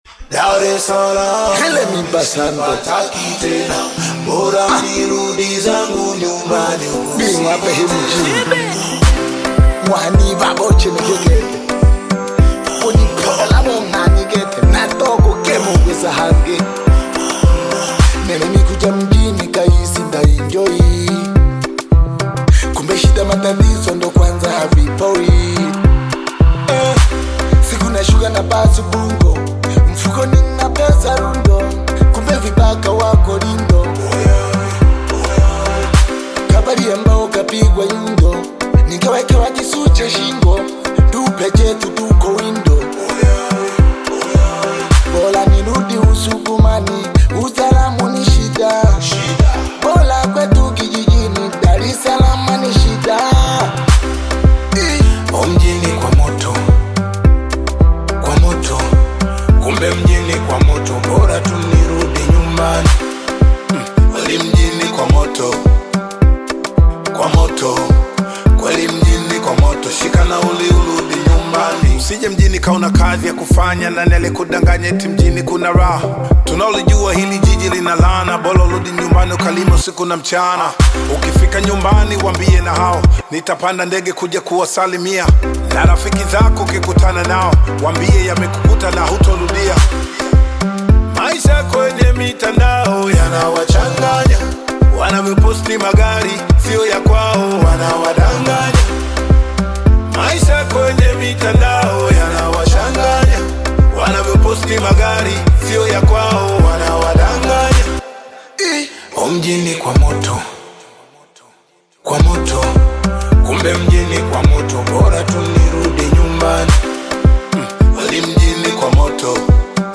Bongo Flava music track
Tanzanian traditional sukuma artist, singer and songwriter
Bongo Flava song